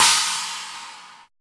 626 CHINA.wav